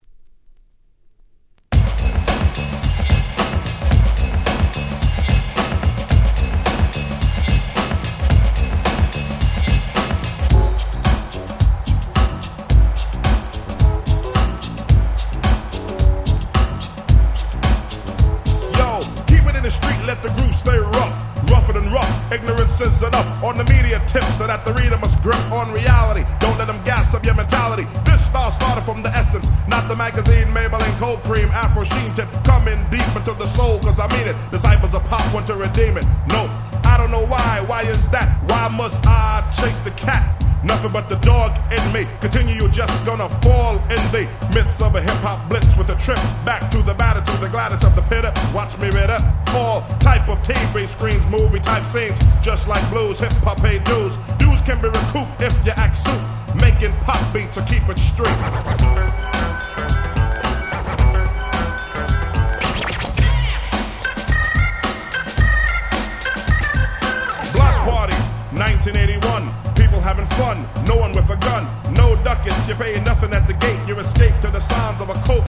チャブロック 90's Boom Bap ブーンバップ